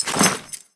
SFX item_get_armor.wav